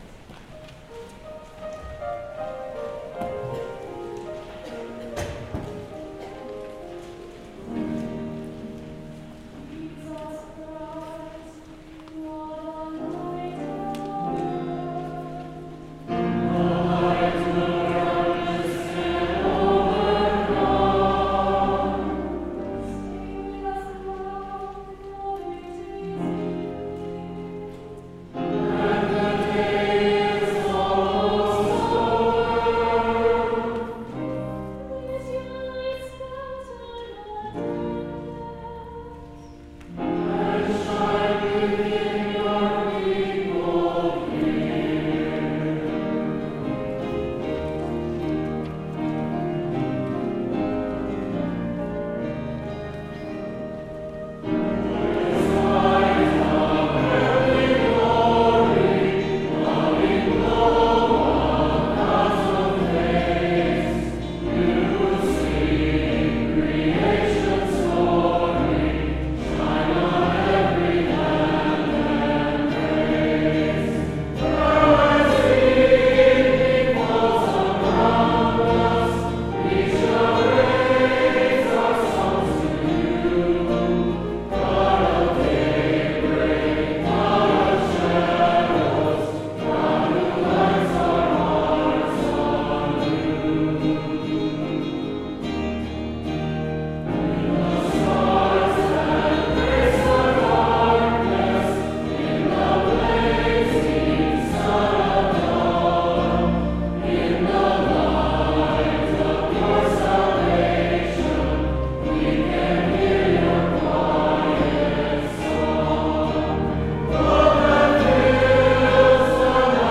piano / organ
cantors
Holden-Evening-Prayer-opening-liturgy.mp3